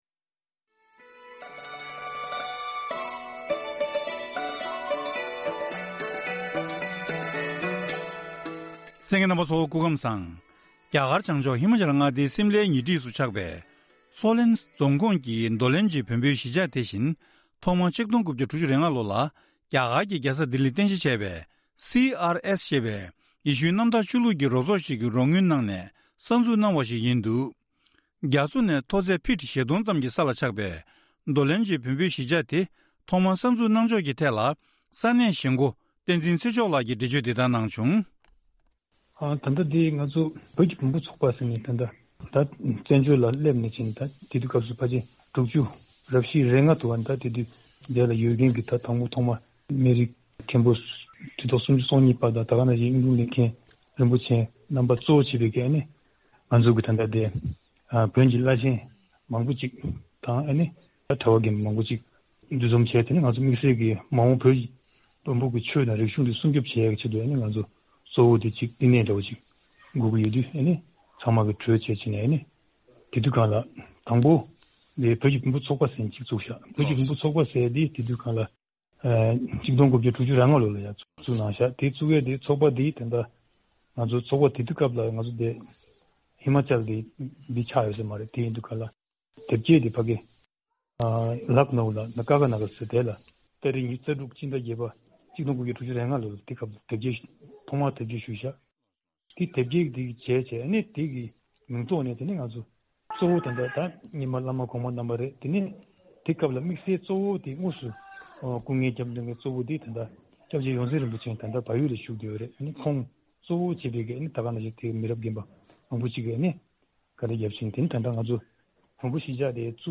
གནས་འདྲི་ཞུས་ནས་ཕྱོགས་སྒྲིག་ཞུས་པ